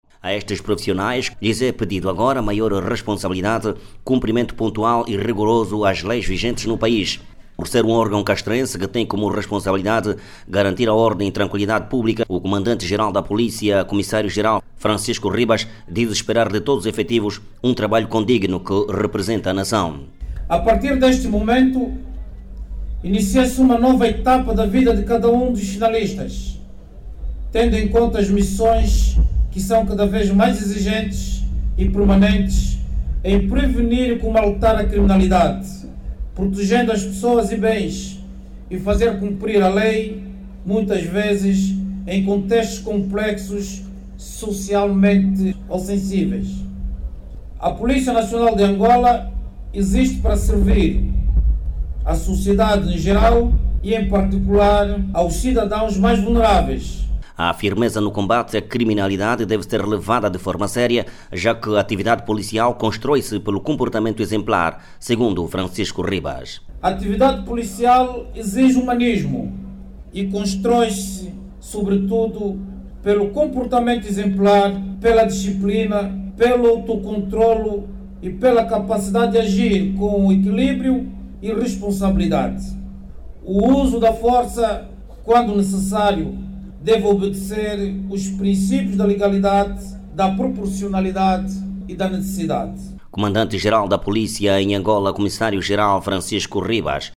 A Polícia Nacional reforçou o órgão com mais de dois mil efectivos com o curso básico de Polícia terminado ontem, quarta-feira(17), em Luanda. Ouça no áudio abaixo toda informação com a reportagem